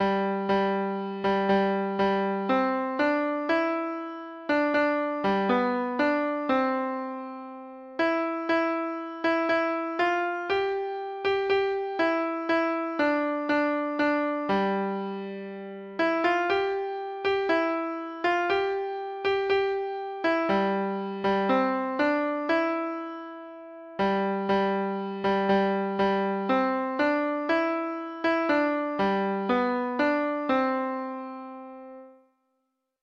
Folk Songs